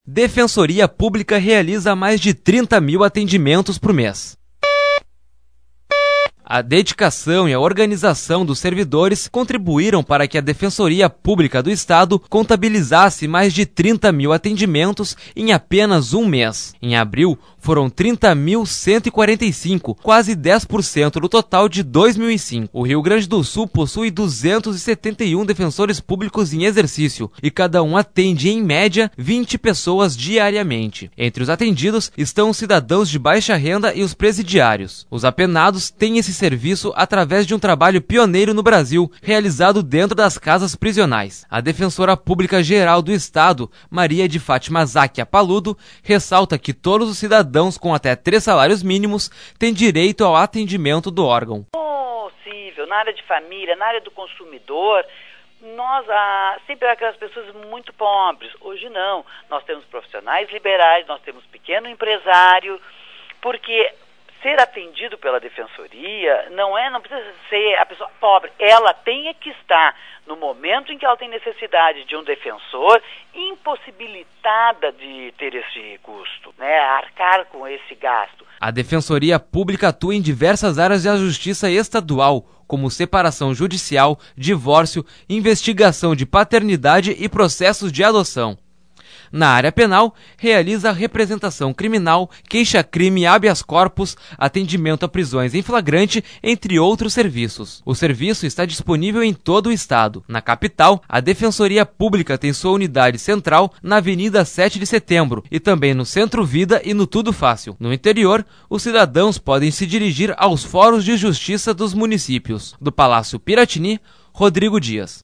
A dedicação e a organização dos servidores contribuíram para que a Defensoria Pública do Estado contabilizasse mais de 30 mil atendimentos em apenas um mês. Sonora: Defensora pública-geral do Estado, Maria de Fátima Záchia PaludoLocal: Porto Alegre - R